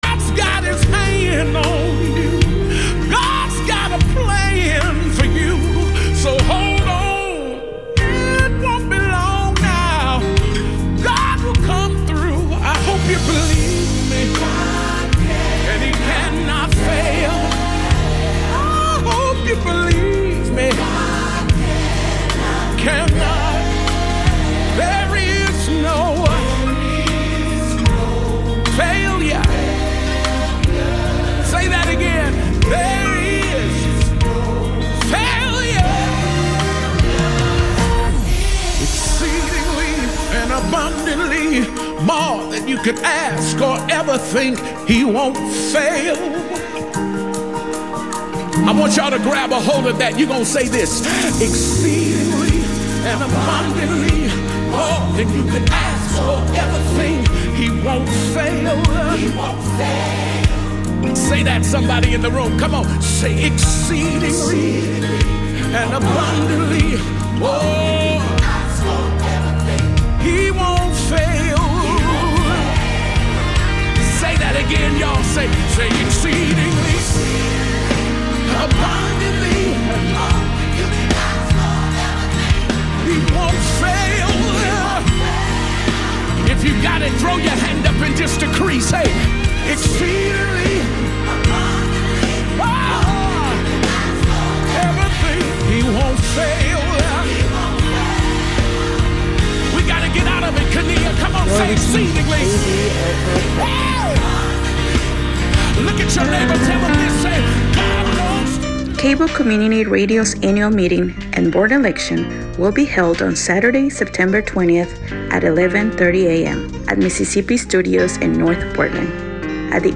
PDX Progressive Talk Radio on 08/31/25